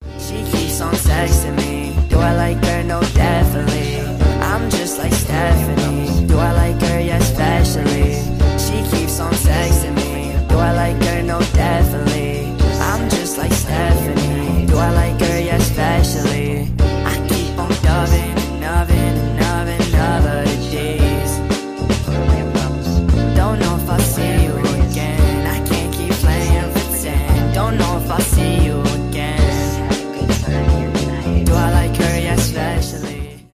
поп , rnb